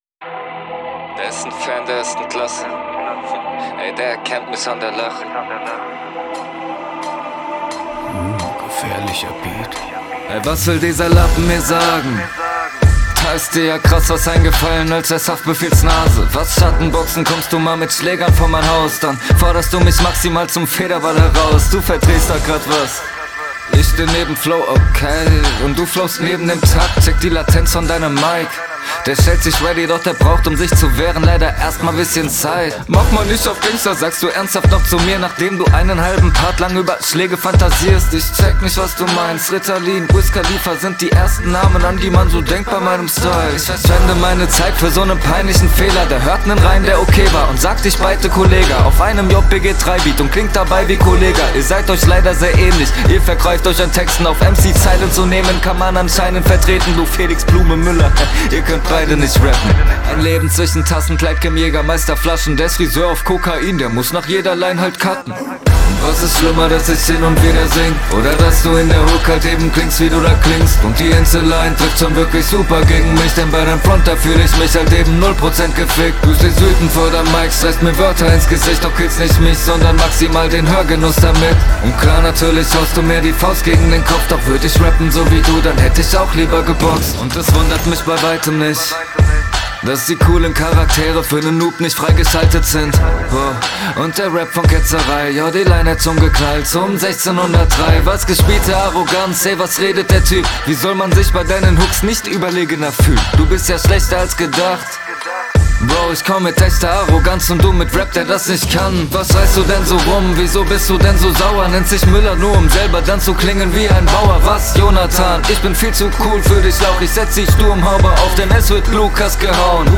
"mmm gefährlicher beat" haha, soundlich wieder nice, flow besser, allgemein das beste aus dem beat …
Was direkt auffällt ist, dass die Hook 1000x mal besser klingt als …